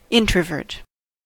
introvert: Wikimedia Commons US English Pronunciations
En-us-introvert.WAV